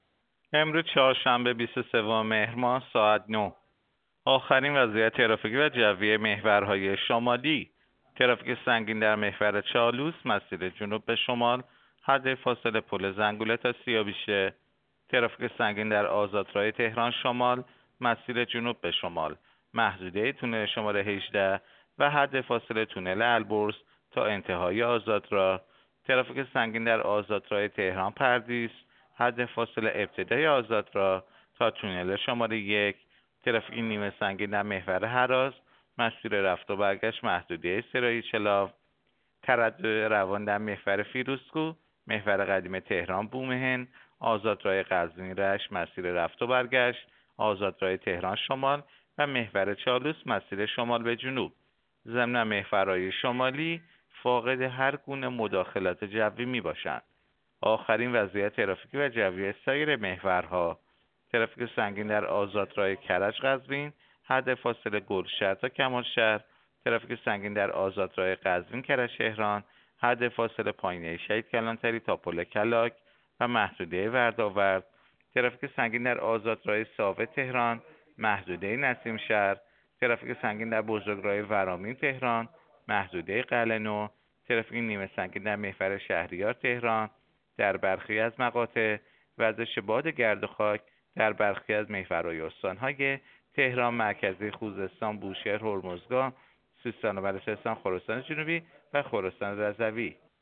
گزارش رادیو اینترنتی از آخرین وضعیت ترافیکی جاده‌ها ساعت ۹ بیست‌وسوم مهر؛